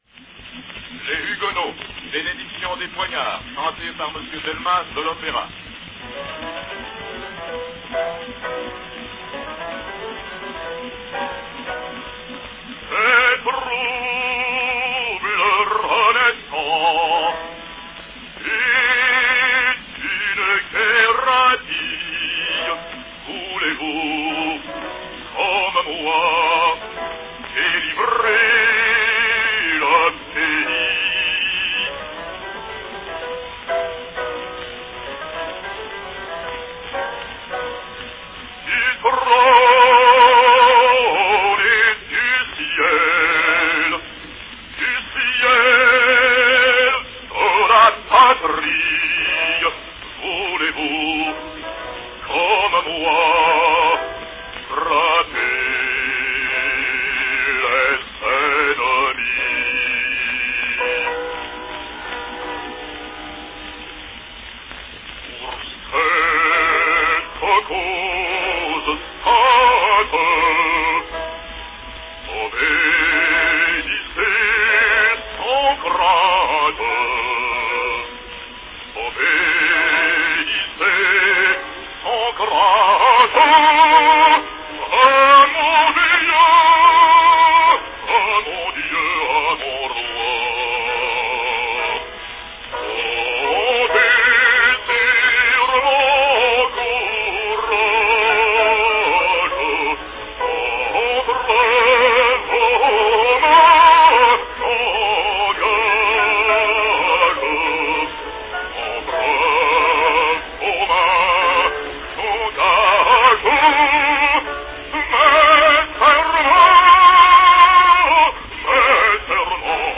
From 1902, French opera great bass singer Jean-Francisque Delmas sings Benediction des Poignards from Les Huguenots.
Cylinder # 2495 (3½" diameter)
Category Bass-baritone
Performed by Jean-Francisque Delmas
Announcement "Les Huguenots - Bénédiction des Poignards, chanté par Monsieur Delmas de l'opéra."
In this unusual format 3½" diameter "salon" cylinder, Delmas sings the "Blessing of the Daggers" from Giacomo Meyerbeer's 1836 opera Les Huguenots.